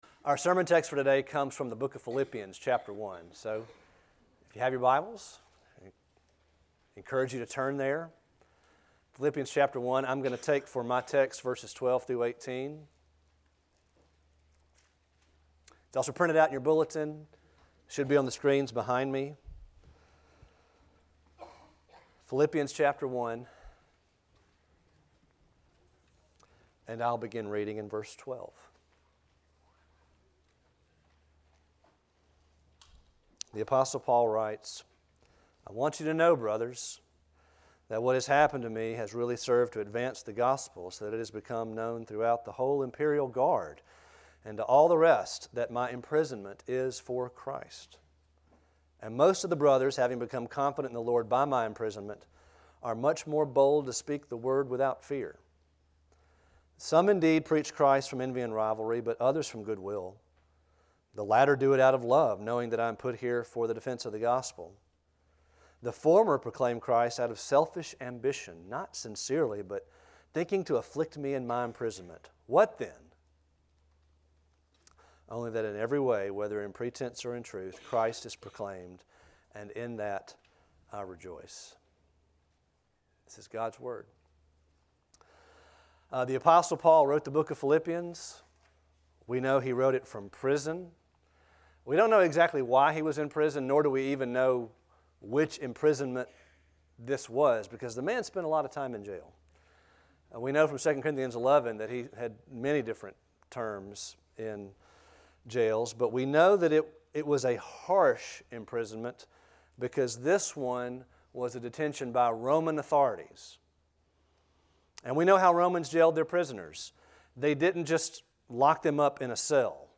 A sermon from the series "Have this Mind in You."